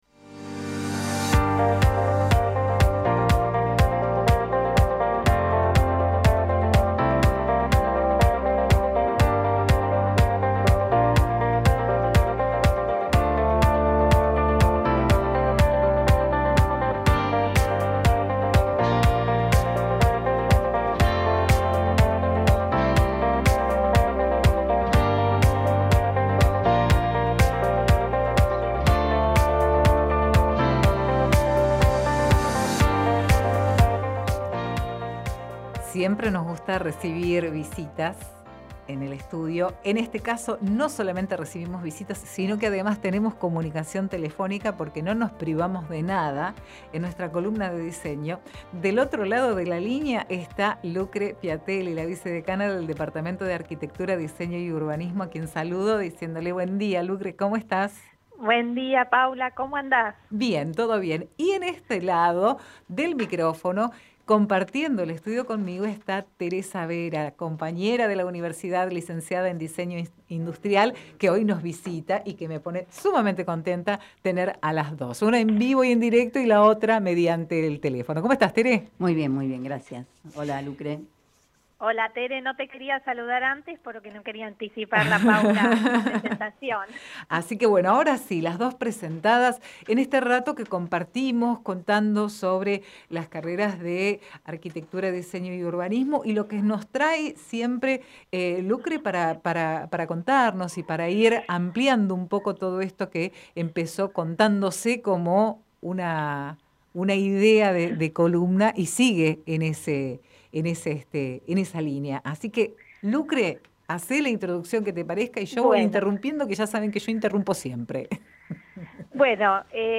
entrevistado/a en estudio